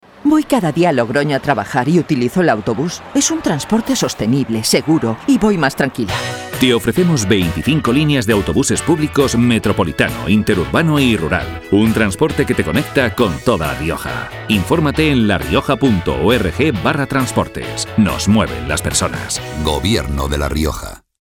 Elementos de campaña Cuñas radiofónicas Cuña genérica.